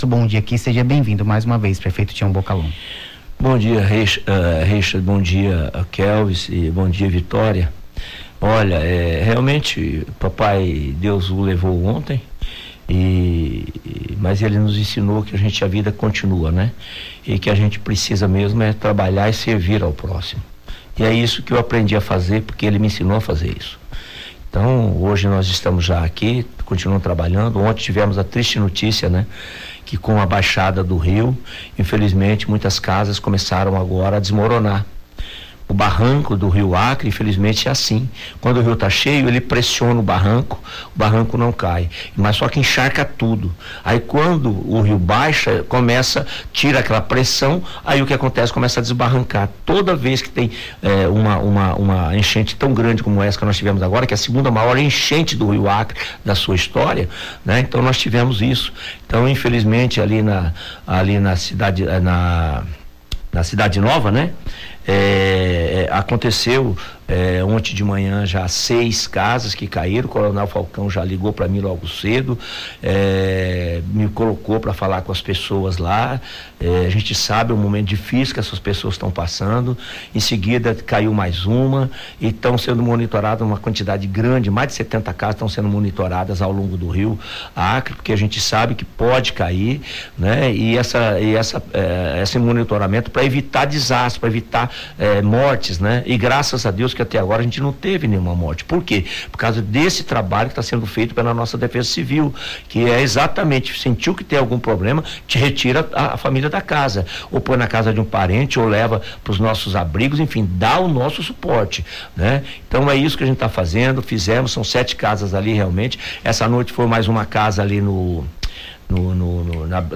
Em entrevista à CBN Amazônia Rio Branco, nesta segunda-feira, o prefeito de Rio Branco, Tião Bocalom (PP) falou sobre as ações da prefeitura no apoio e atendimento às famílias que tiveram suas casas destruídas no último domingo, no bairro Cidade Nova.